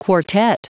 Prononciation du mot quartet en anglais (fichier audio)
Prononciation du mot : quartet